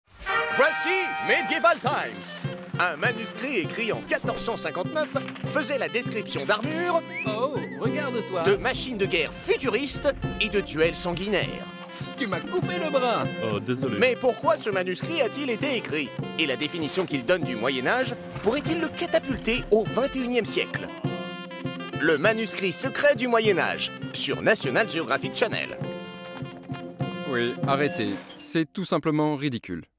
Prestation de voix-off "Le manuscrit secret" : ton médium, parodique, drôle, dynamique
Voix très parodique au style Monty Python.
Pour ce projet, on m’a demandé d’emprunter une tonalité médium, parodique, ringarde, drôle, dynamique, et ridicule.
Une voix médium, parodique, ringarde, drôle, dynamique, ridicule, qui a su, je l’espère, donner une nouvelle dimension à cette bande-annonce, et contribuer à son succès.